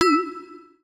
Universal UI SFX / Clicks
UIClick_Marimba Metal Wobble 01.wav